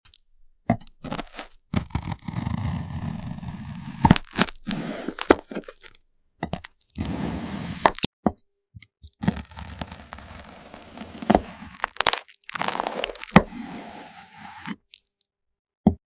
Pure satisfying sounds: frozen crack, creamy crunch, and icy crumble.
Feel the frozen snap as the knife cuts through rich cocoa layers, a street classic in Singapore & Indonesia. Just pure ASMR chocolate slicing.